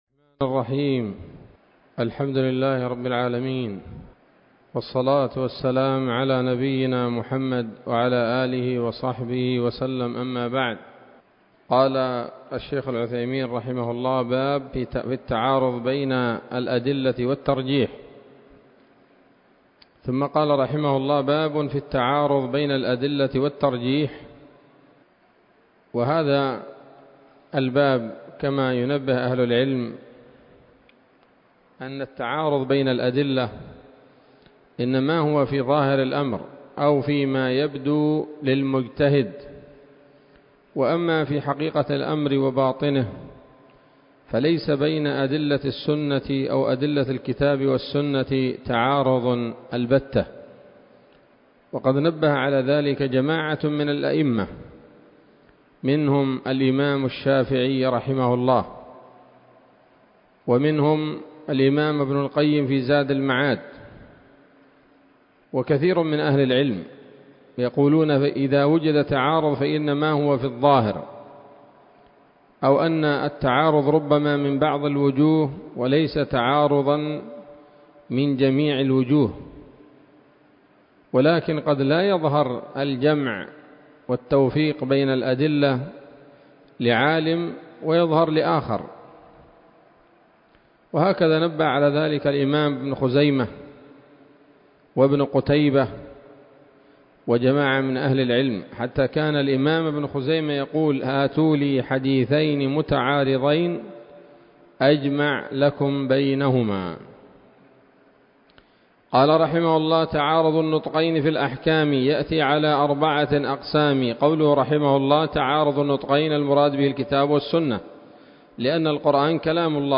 الدرس الثاني والخمسون من شرح نظم الورقات للعلامة العثيمين رحمه الله تعالى